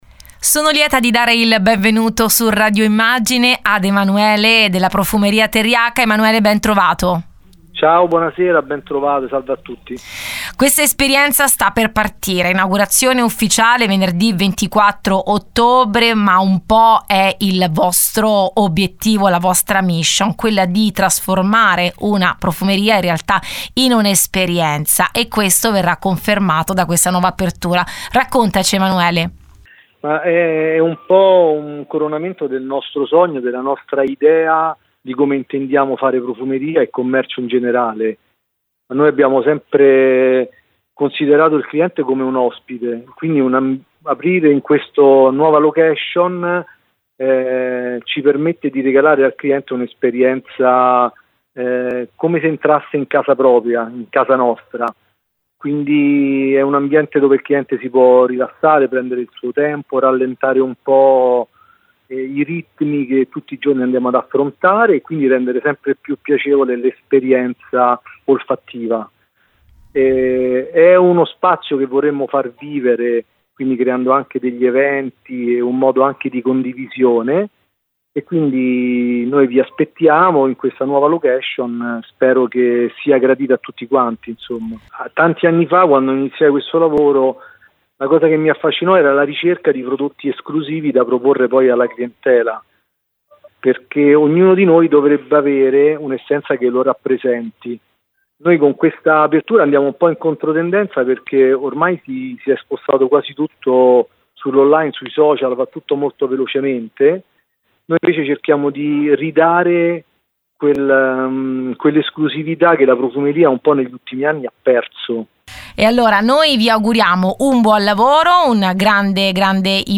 ai microfoni di Radio Immagine